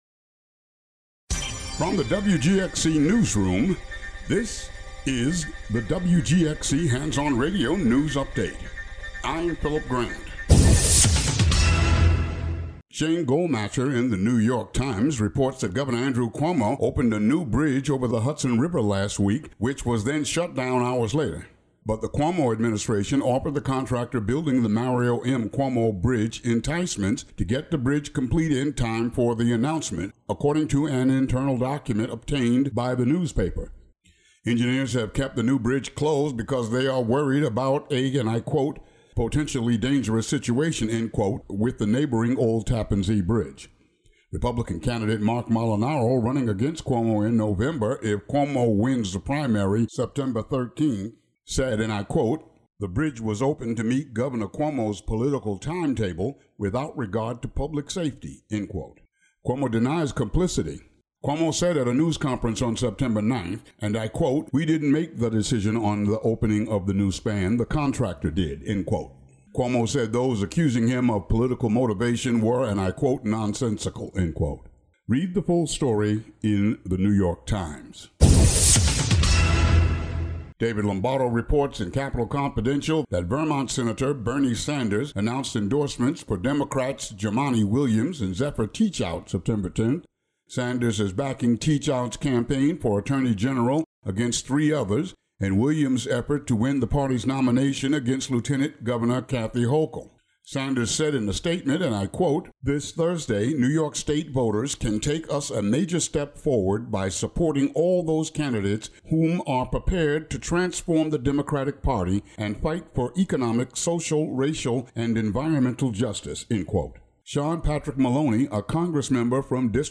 News update for the area.